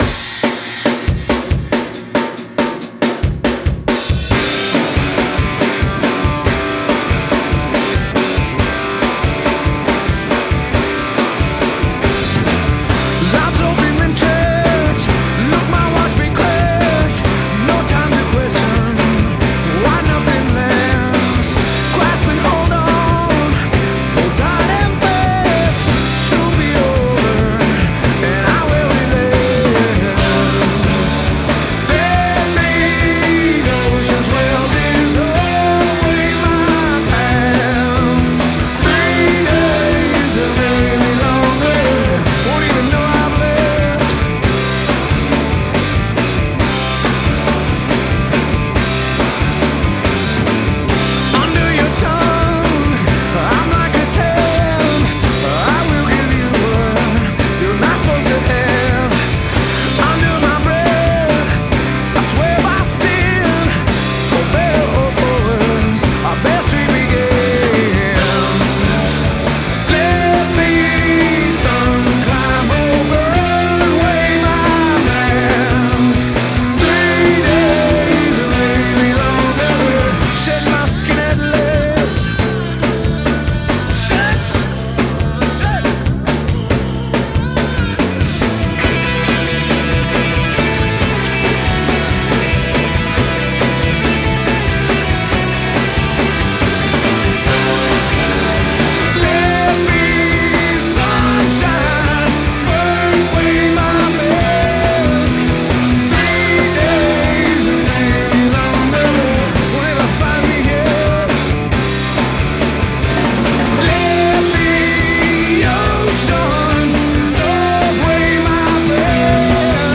Alternative Rock / Grunge